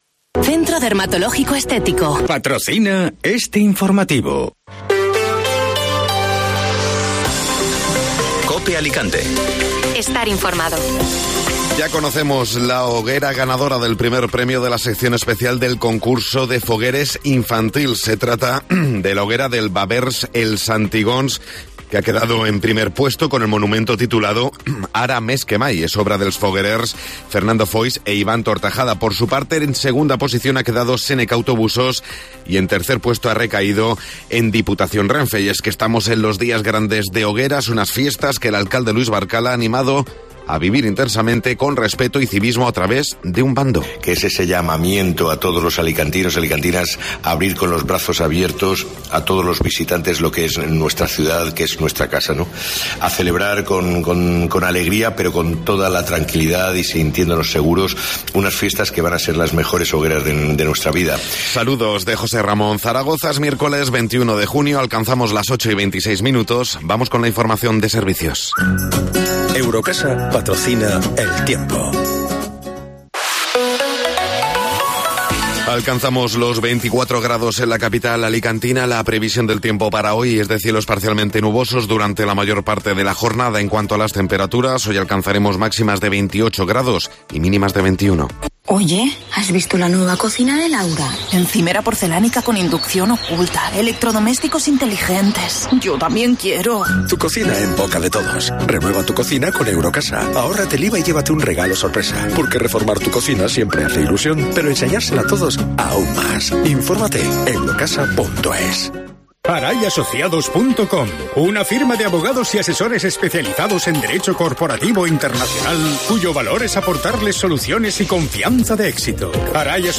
Informativo Matinal (Miércoles 21 de Junio)